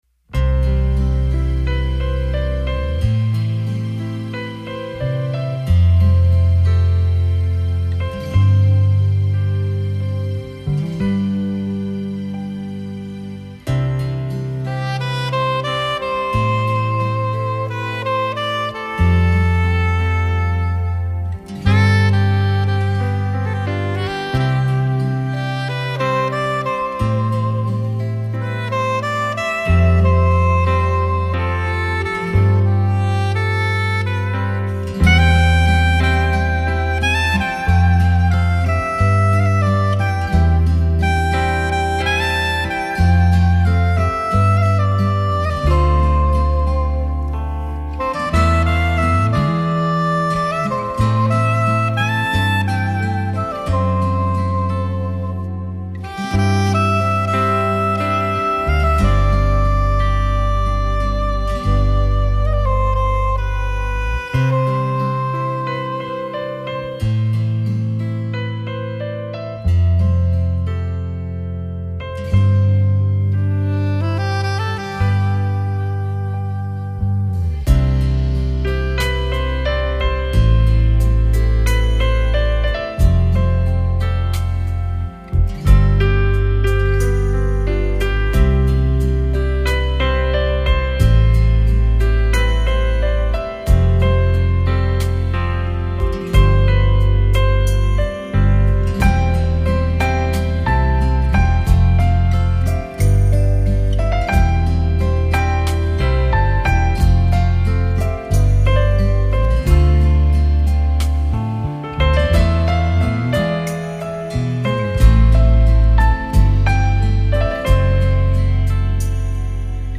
音乐风格: Instrumental / Saxophone / Smooth Jazz